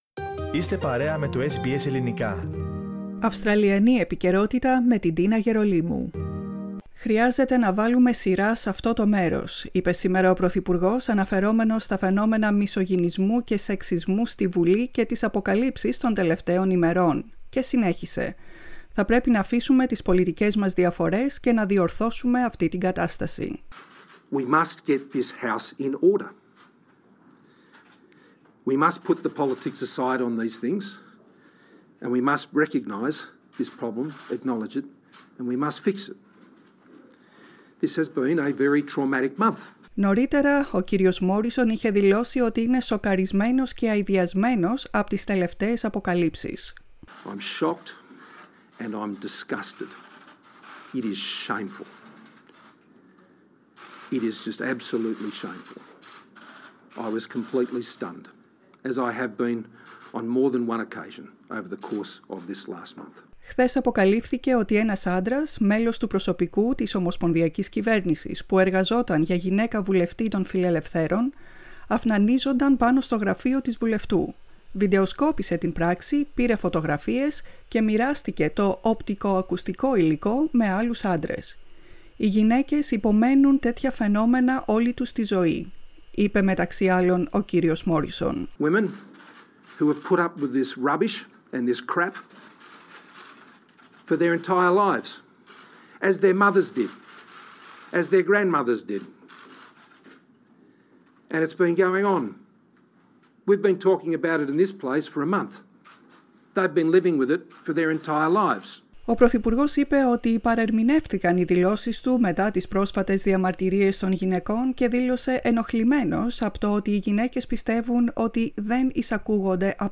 Scott Morrison speaks during a press conference at Parliament House in Canberra, Tuesday, March 23, 2021.